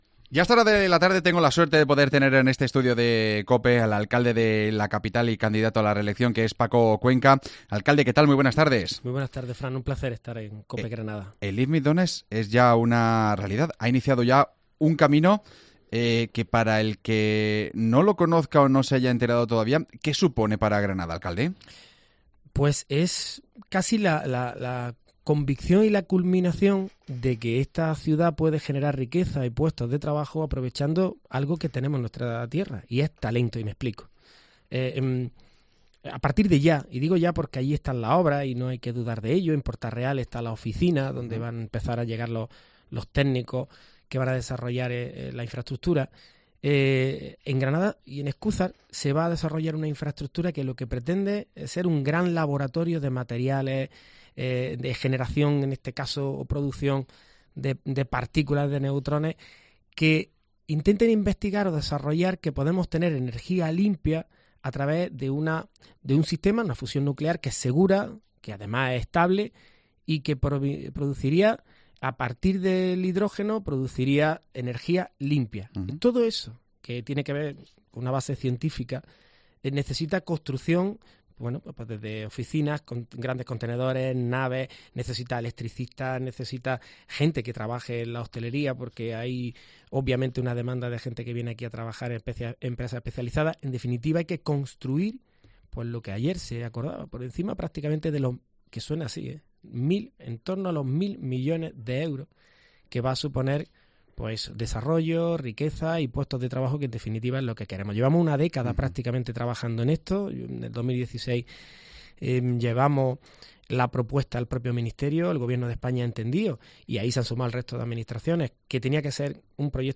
AUDIO: Escucha la entrevista al alcalde de Granada repasando los principales temas de actuales